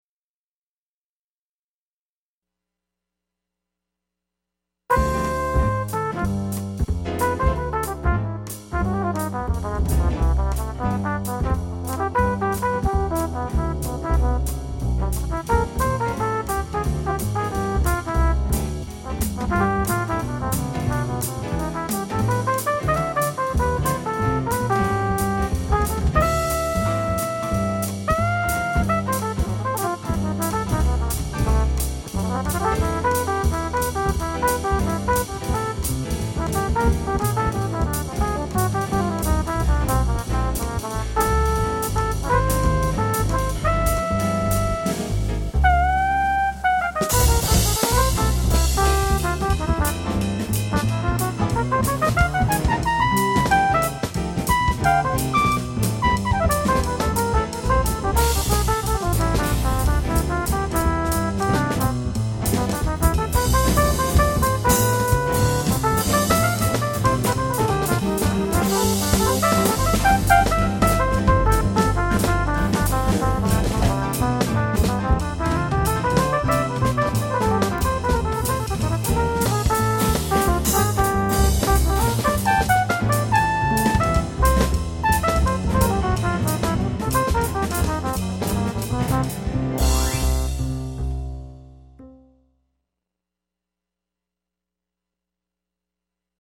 Jazz (quartet)